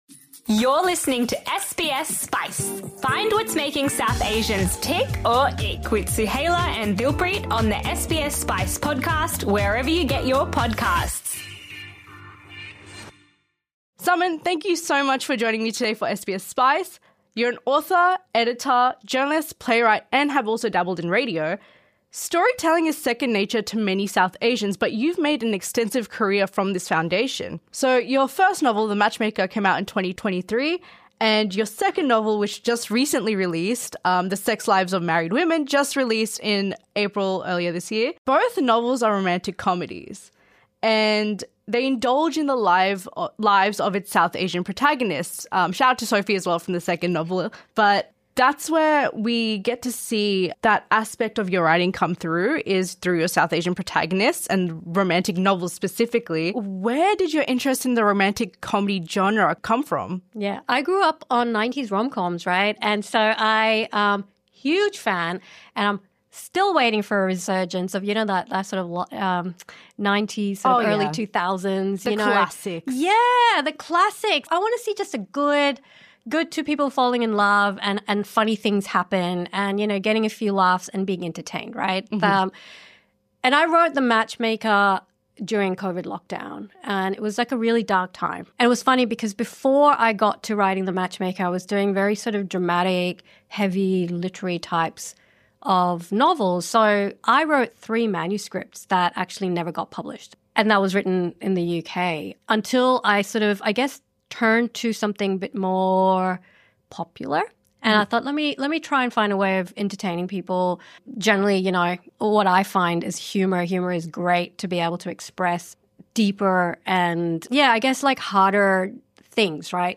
A brave, funny, and moving conversation about intimacy, friendship, and the freedom to want.